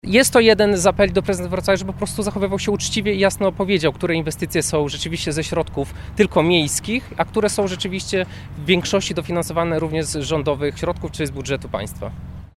Podczas konferencji skierowano apel do Prezydenta Wrocławia.